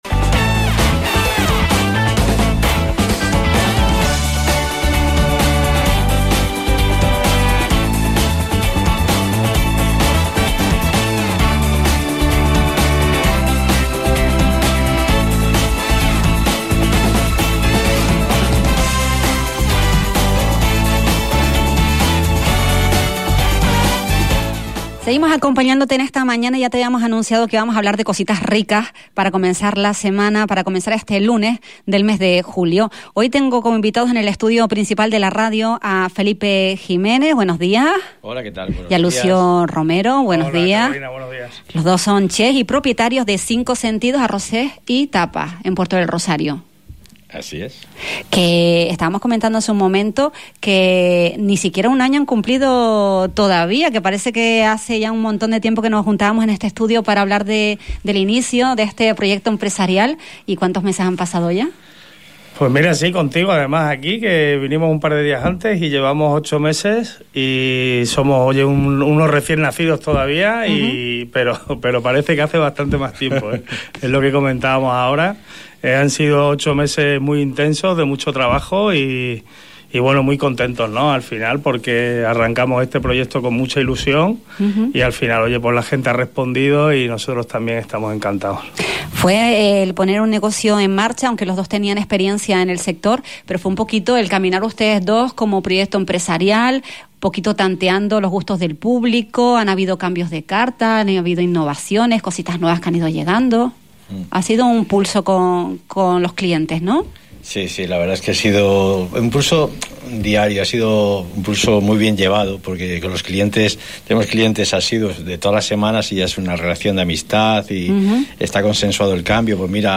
Esta mañana visitaron los estudios de Radio Insular Fuerteventura